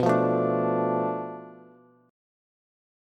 Bm7b5 Chord
Listen to Bm7b5 strummed